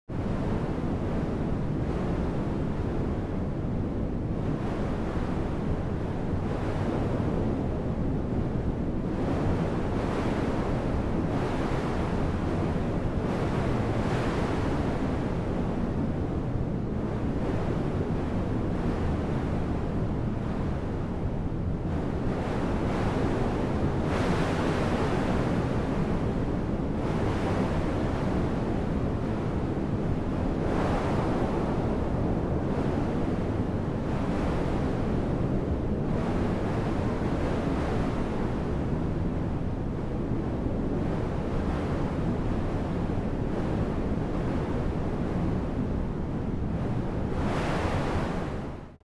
دانلود آهنگ باد 22 از افکت صوتی طبیعت و محیط
دانلود صدای باد 22 از ساعد نیوز با لینک مستقیم و کیفیت بالا
جلوه های صوتی